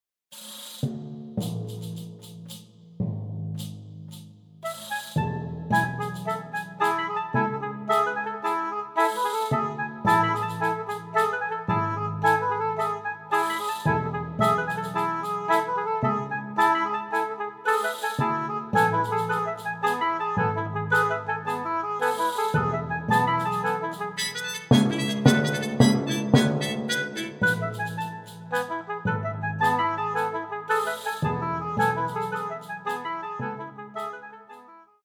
Genre: Klassische Musik
Stereo